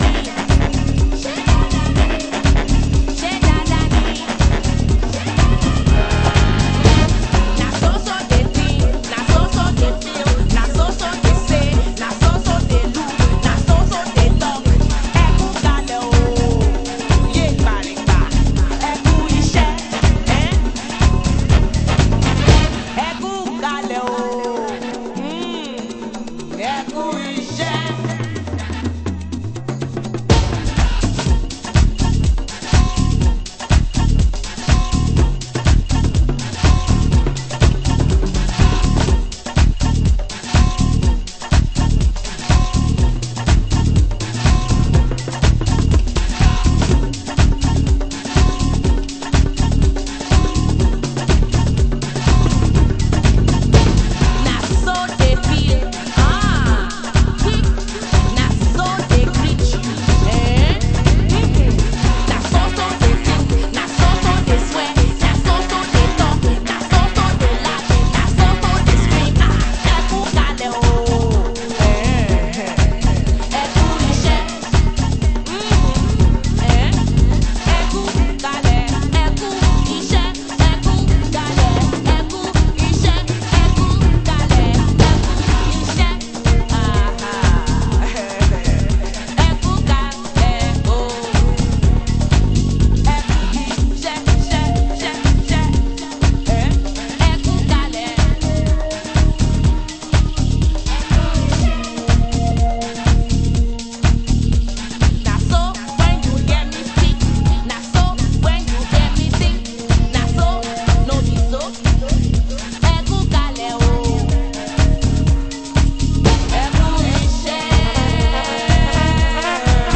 Afro Beat Mix
Afro House Mix